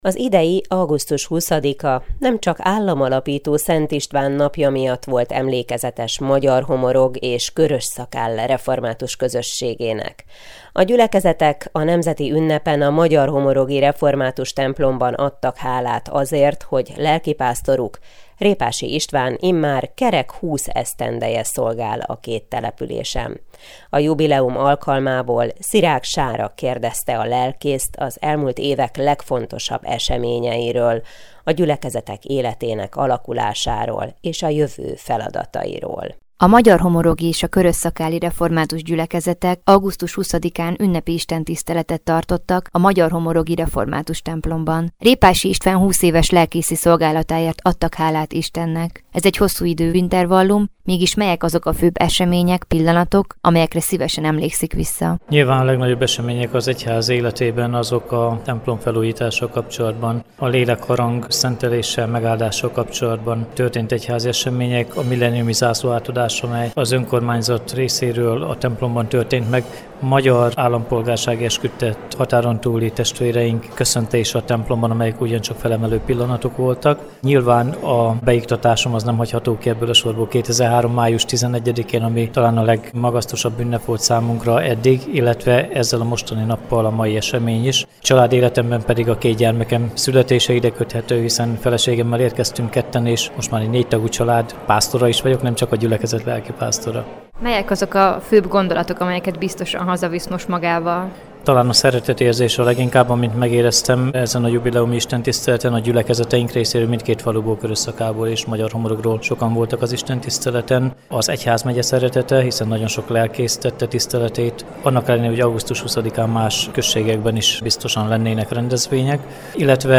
Ünnepi istentisztelet Magyarhomorogon
A teljes interjú, és Fekete Károly köszöntője alább hallgatható meg: